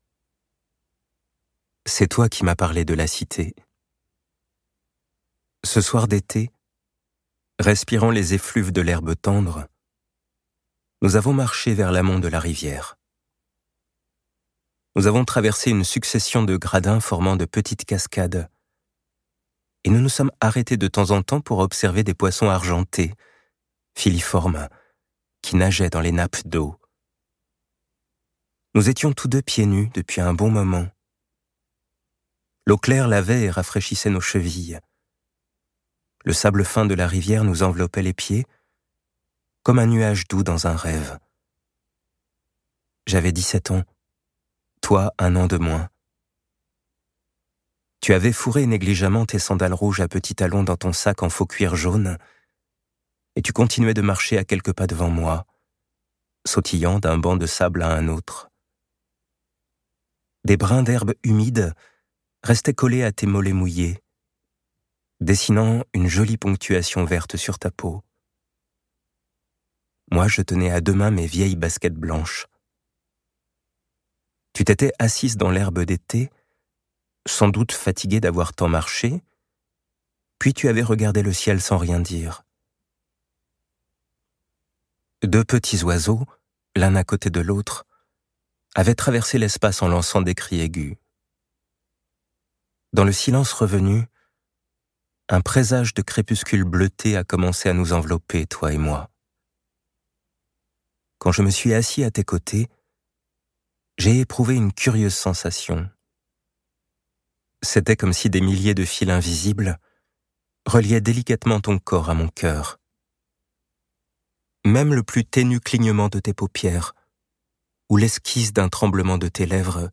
Click for an excerpt - La Cité aux murs incertains : le nouveau roman de Haruki Murakami – son dernier livre best-seller traduit en version française - nouveauté 2025 de Haruki MURAKAMI